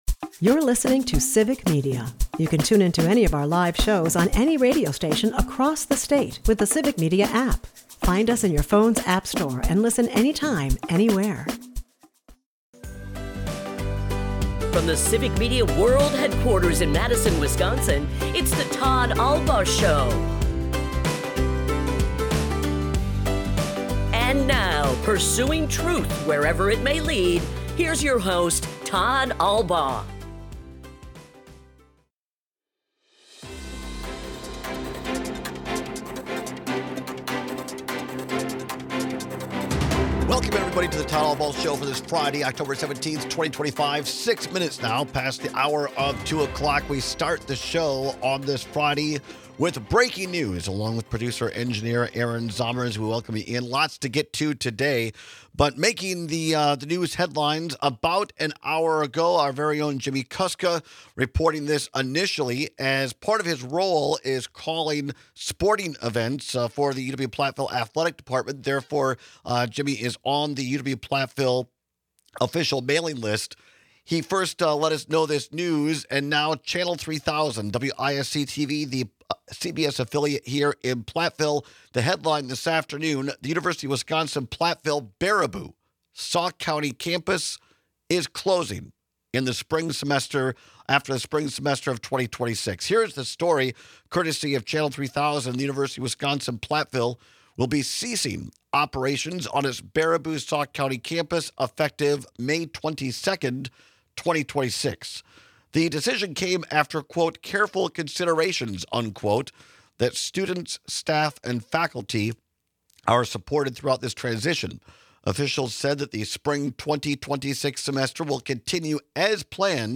We speak with former Republican State Senate Majority Leader Dale Schultz, who has always been a big advocate for government transparency.